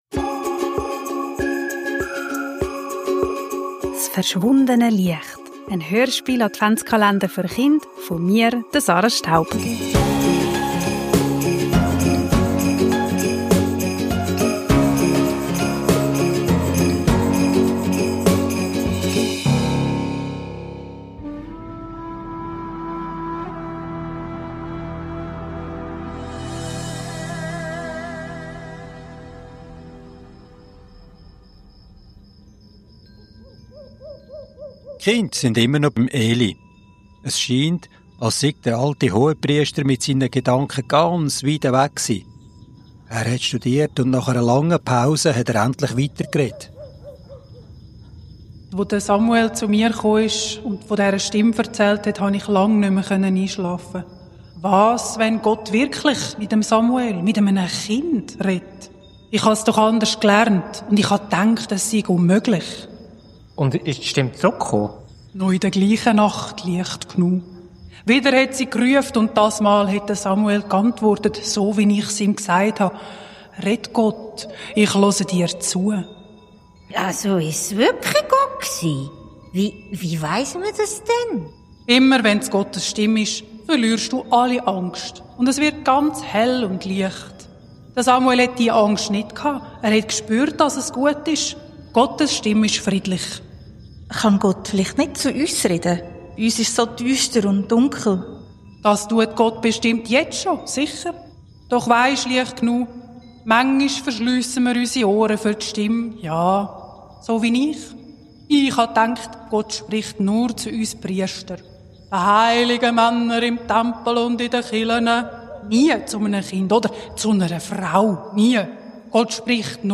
s' verschwundene Liecht - en Adventshörspiel-Kalender für Chind
Kinder, Advent, Hörspiel, Weihnachten, Kindergeschichte